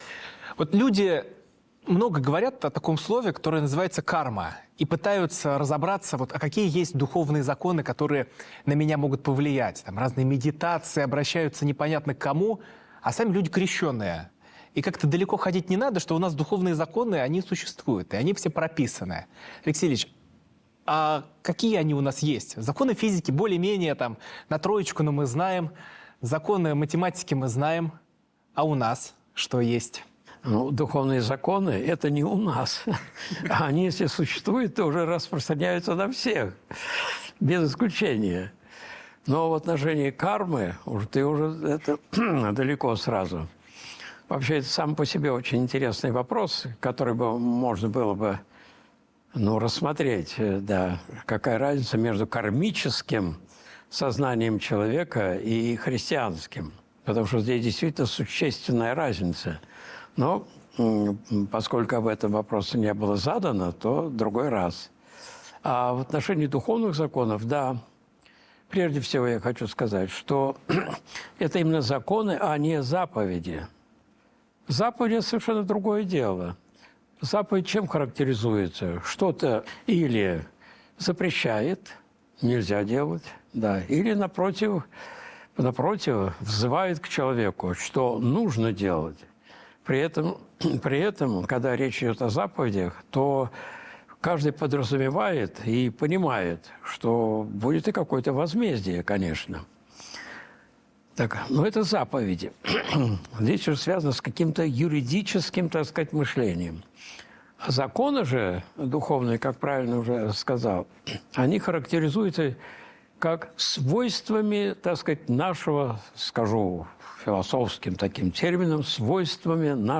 Видеолекции протоиерея Алексея Осипова